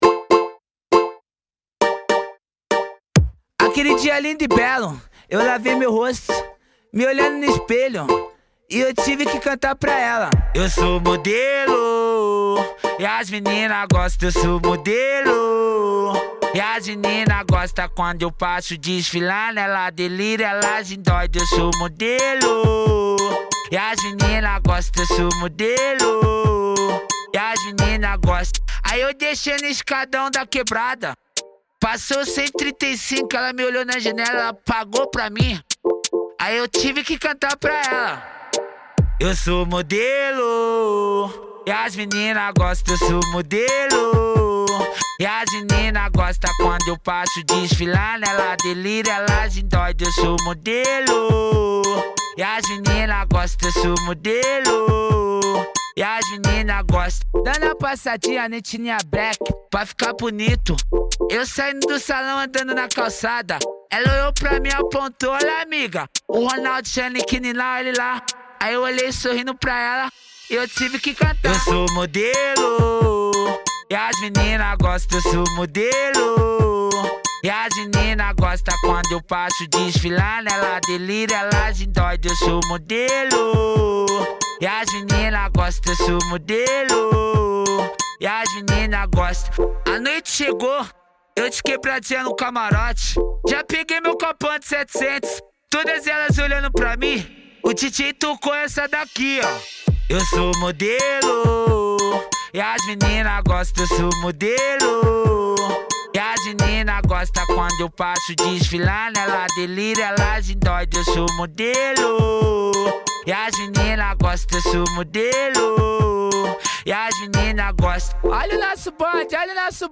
2024-04-01 21:24:40 Gênero: Funk Views